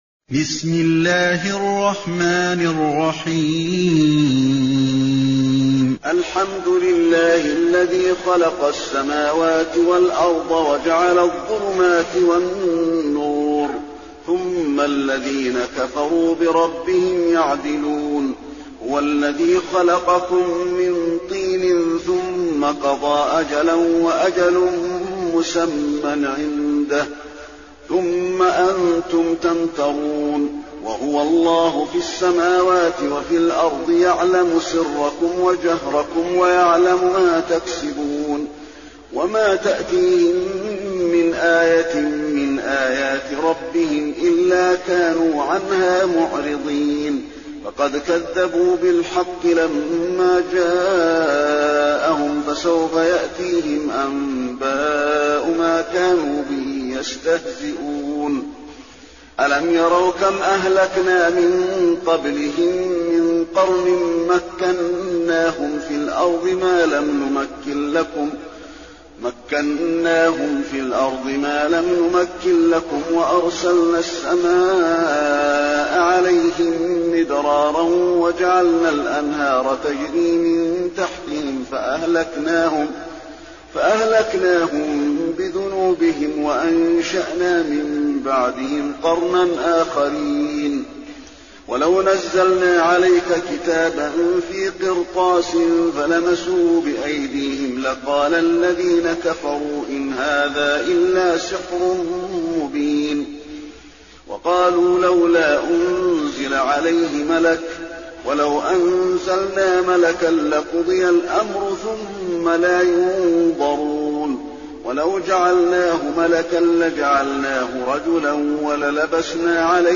المكان: المسجد النبوي الأنعام